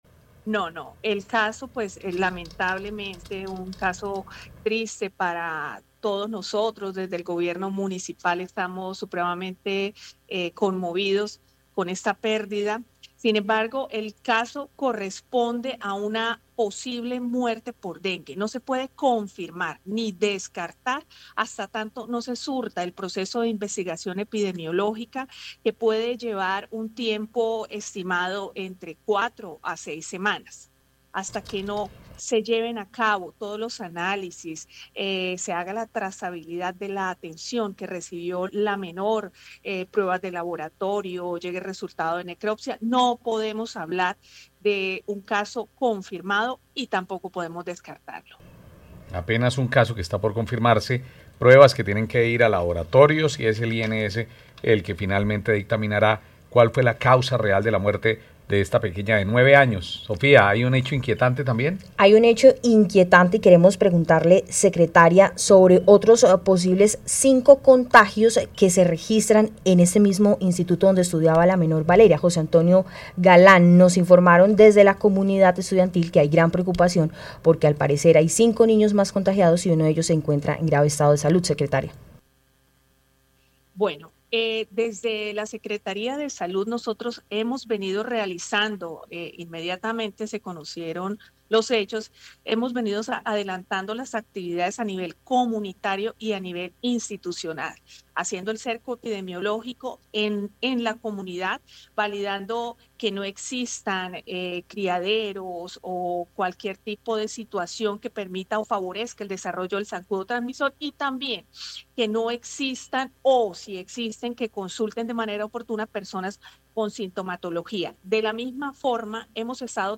GENNY URIBE, SECRETARIA DE SALUD DE FLORIDABLANCA